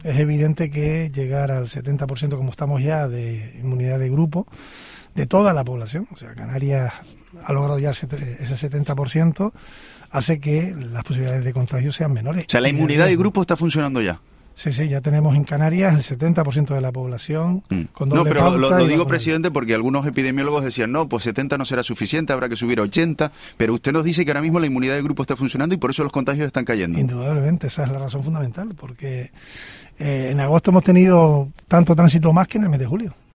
Ángel Víctor Torres, presidente del Gobierno de Canarias